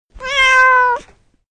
Cat sms tone free download ringtone free download
Animals sounds